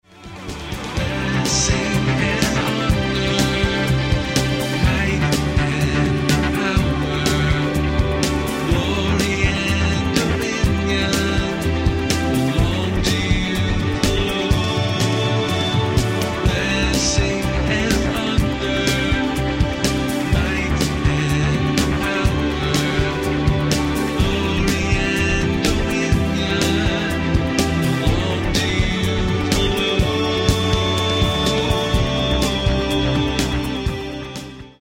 a broad spectrum of musical sounds